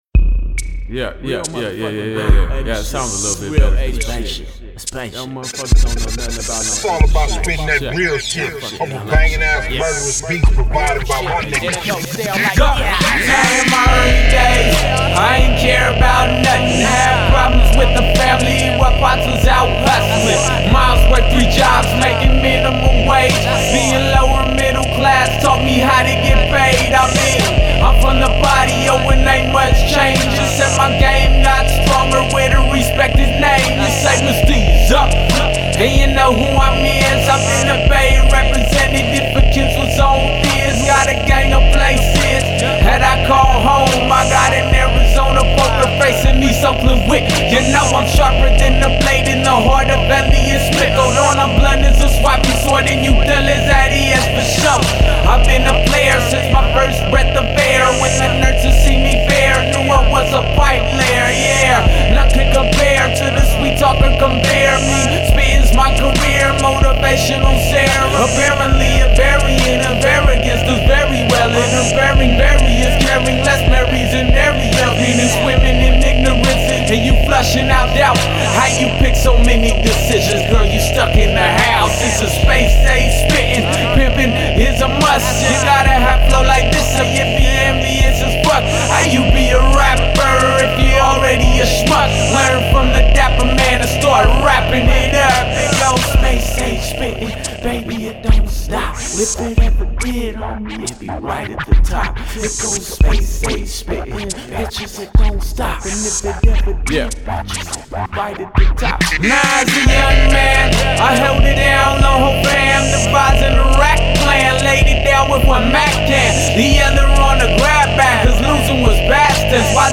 the way the beat incessantly grinds through the whole song